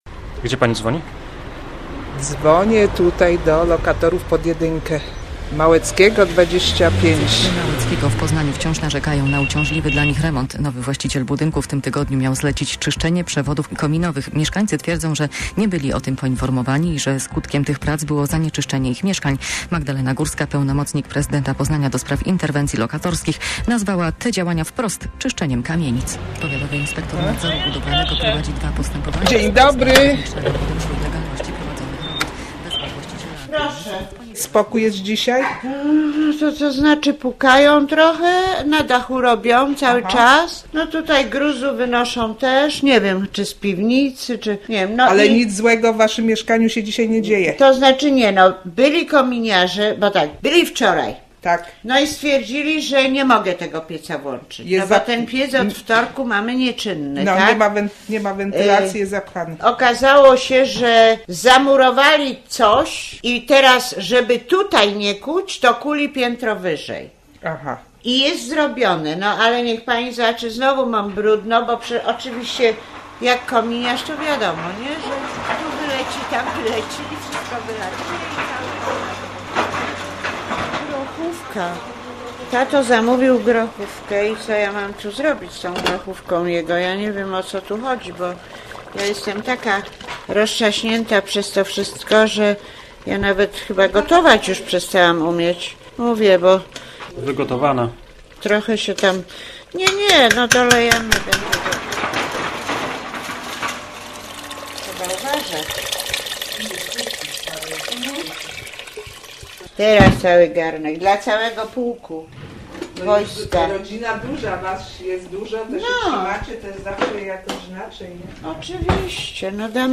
t08tdwgr871fdhz_reportaz_moze_juz_nas_nie_bedzie.mp3